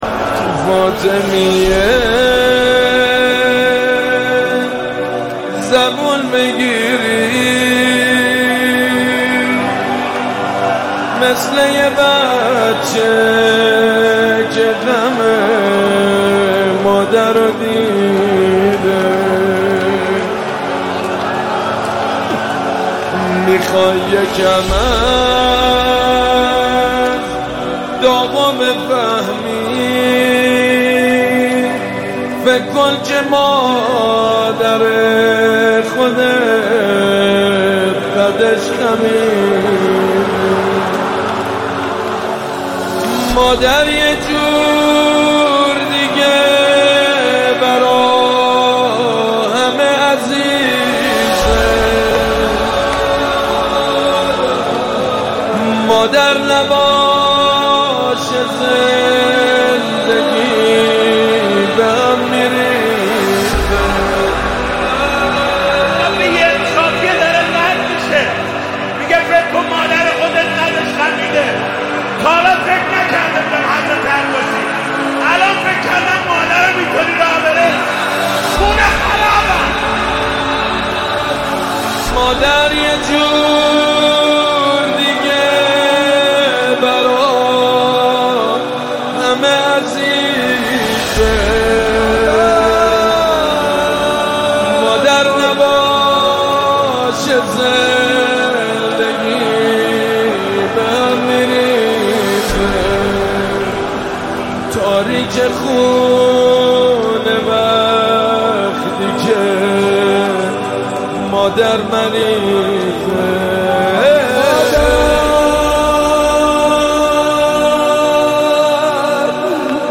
نماهنگ مداحی